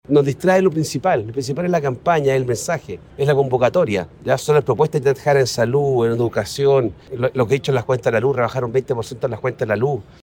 Anoche, en conversación con CNN Chile, el parlamentario afirmó que el Ejecutivo debería tomar distancia de la contienda electoral, argumentando que “cuando uno es candidato del oficialismo; de un partido del Gobierno, y ese Gobierno puede tener dificultades o la valoración ciudadana no es la más alta, eso implica un cierto desafío a superar“.